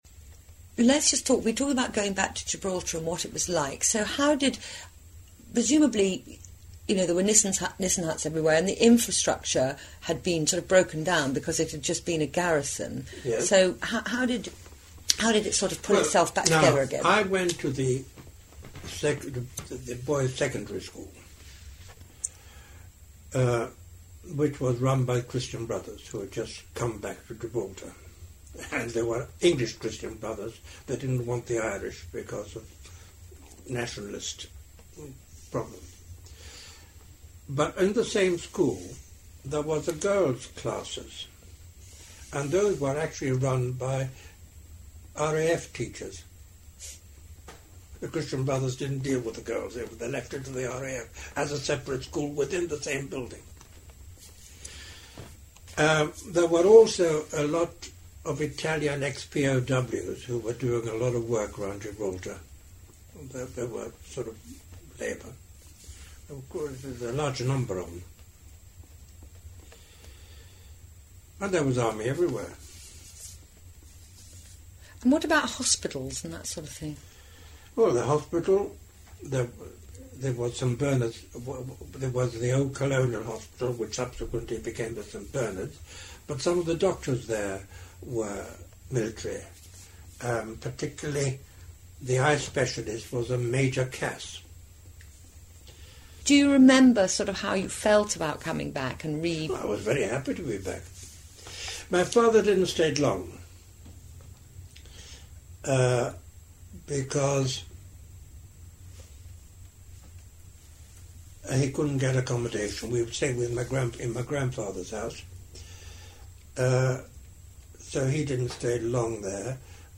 Friends of Gibraltar Oral History